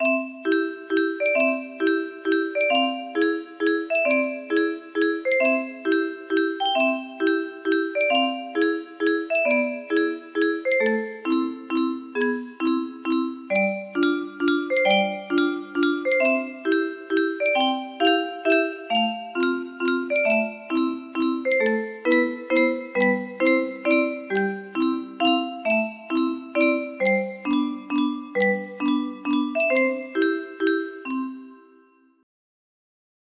julia music box melody